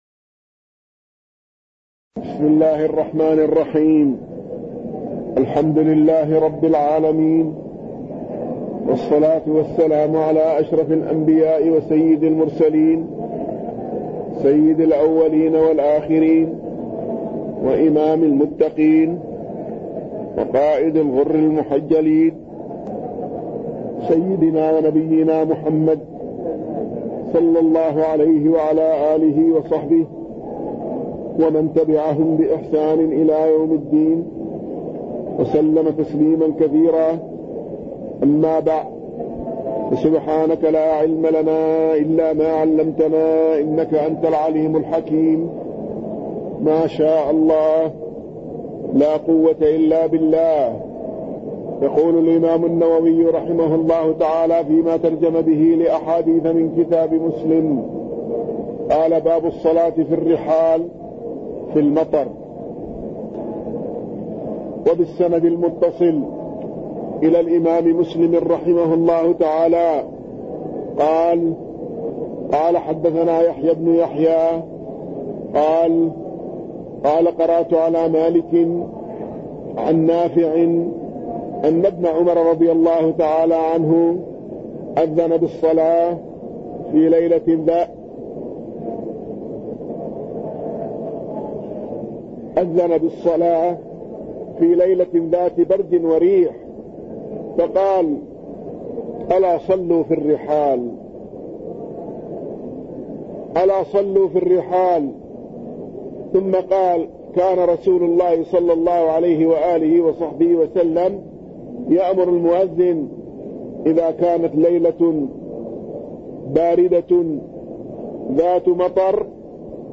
تاريخ النشر ١٥ جمادى الآخرة ١٤٣٠ هـ المكان: المسجد النبوي الشيخ